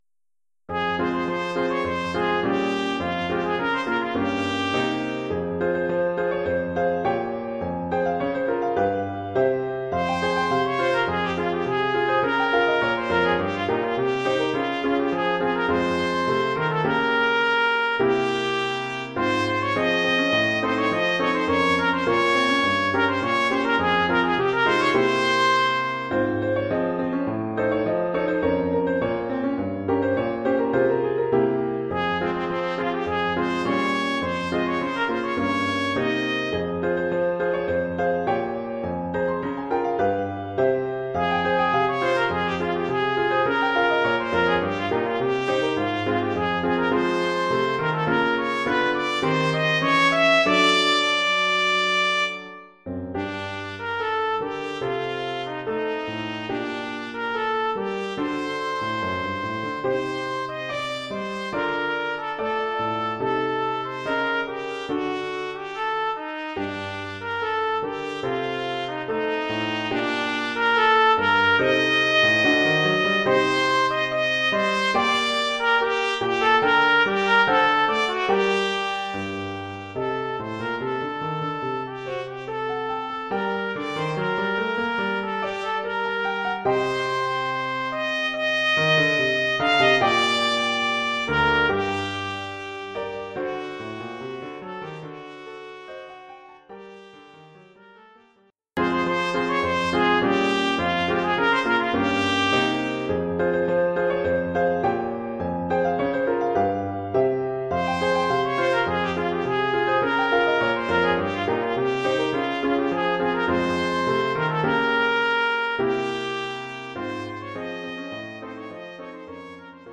Oeuvre pour trompette ou cornet
ou bugle et piano..